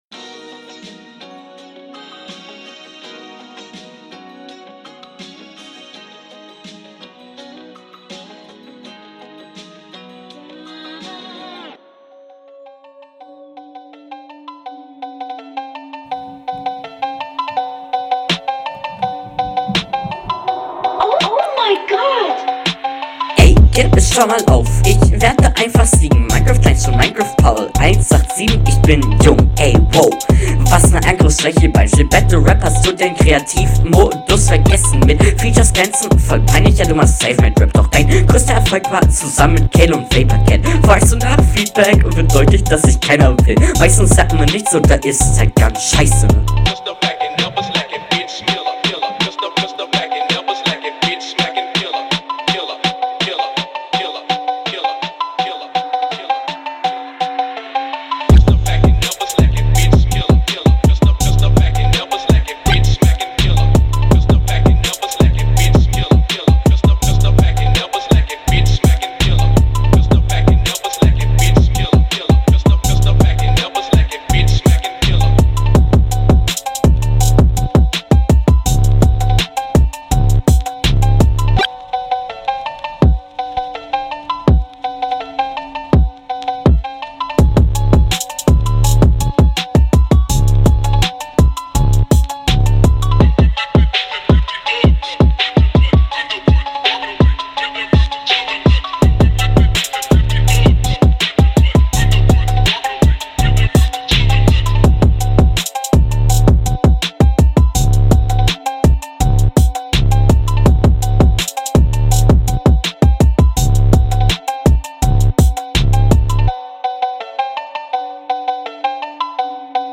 Flow: flow bessert sich nur trotzdem Takt Probleme und alles was ich in Hr2 gesagt …